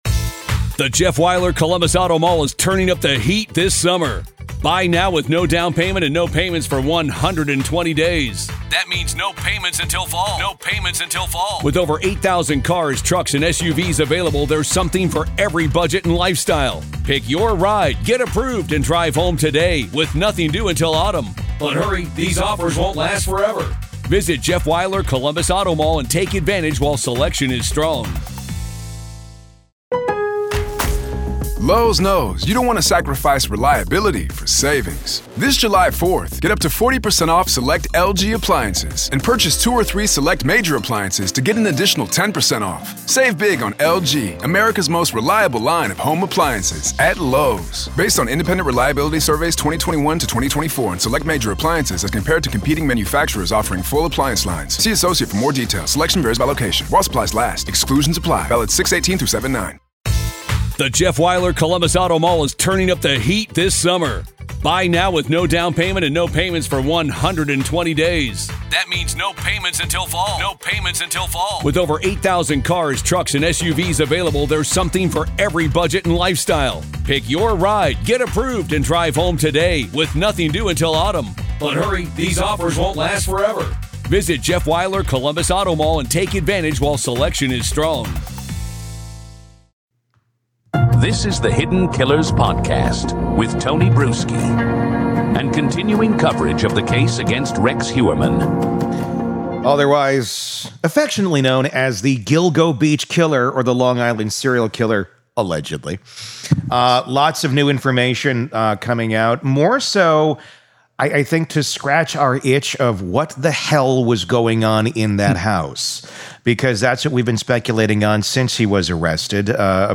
How does a man accused of brutally murdering seven women over three decades hide such horrific crimes from the family sleeping under the same roof? In this eye-opening interview